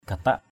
/ɡ͡ɣa-taʔ/ (cv.) katak ktK [Cam M]